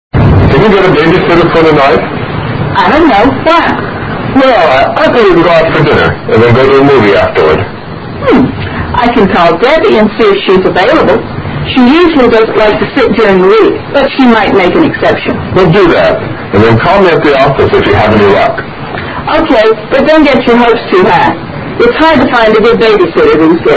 Dialogue 16